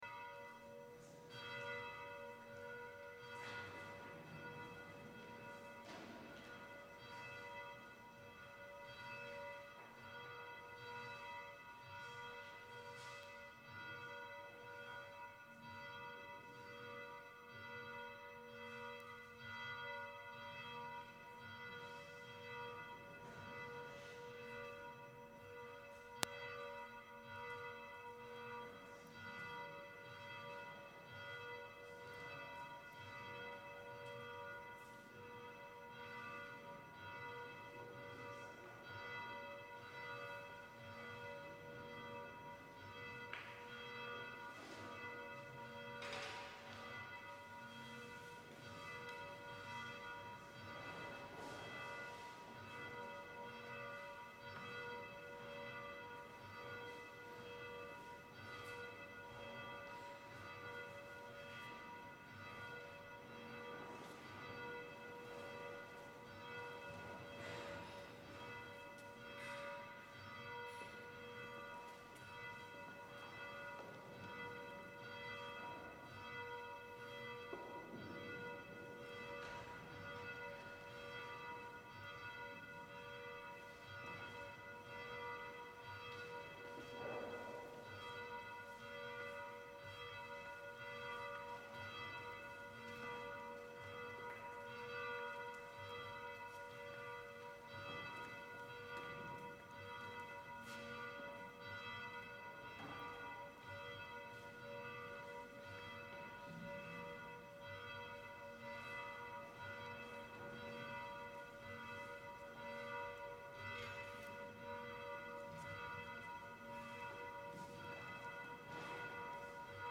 Gregorian
Chanting Monk